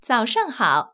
ivr-good_morning.wav